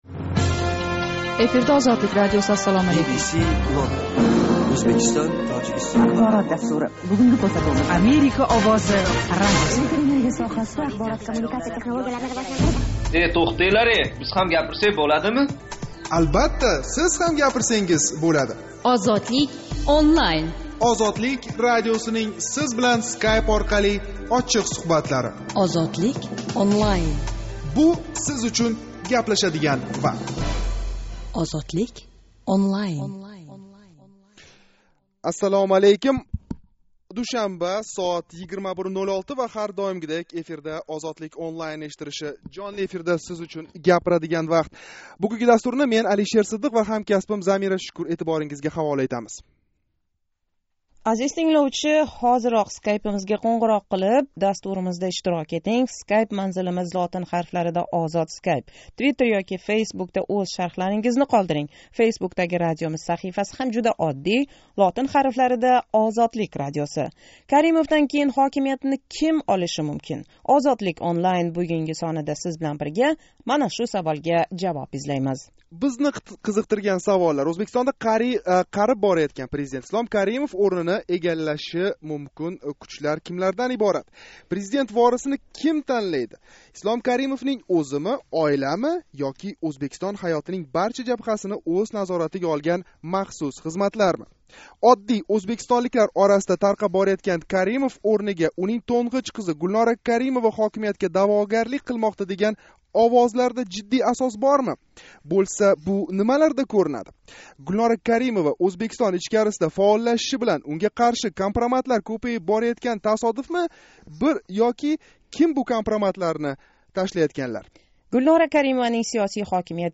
OZODLIK ONLINE жонли¸ интерактив дастурининг 10 сентябр¸ душанба куни Тошкент вақти билан 21:05 да бошланган янги сони ана шу саволга бағишланди.